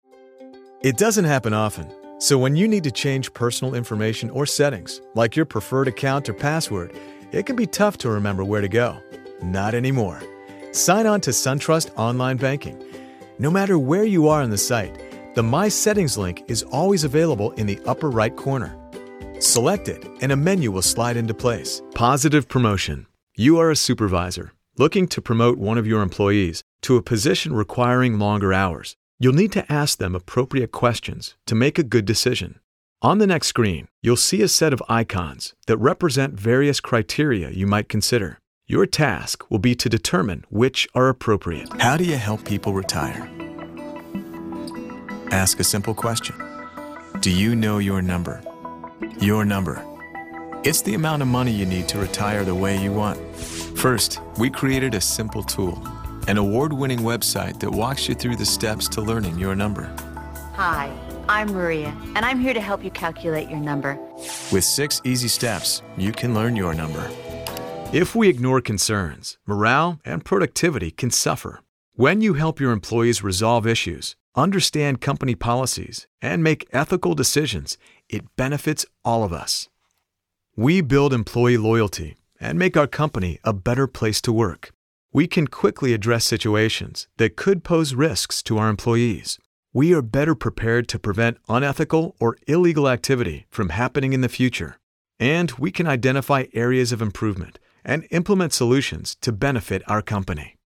All of our contracted Talent have broadcast quality home recording studios.
Corporation Narration Demo audio.mp3